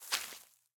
Minecraft Version Minecraft Version snapshot Latest Release | Latest Snapshot snapshot / assets / minecraft / sounds / block / big_dripleaf / tilt_up1.ogg Compare With Compare With Latest Release | Latest Snapshot
tilt_up1.ogg